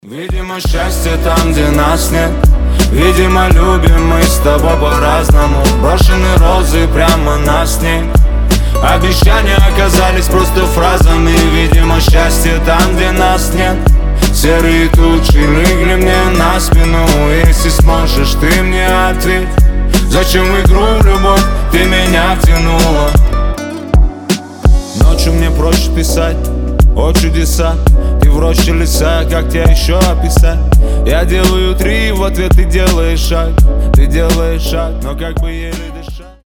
• Качество: 320, Stereo
мужской голос
лирика
грустные
спокойные